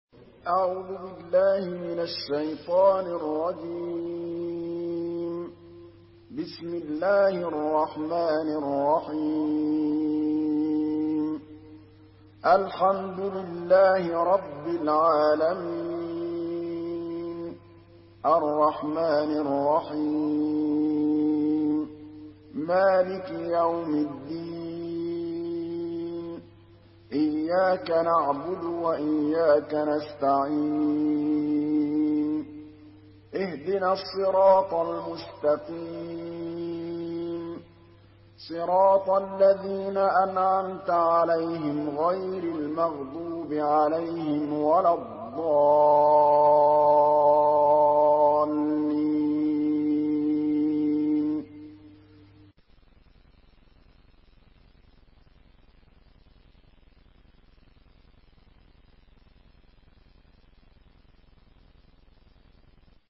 Surah আল-ফাতিহা MP3 by Muhammad Mahmood Al Tablawi in Hafs An Asim narration.
Murattal Hafs An Asim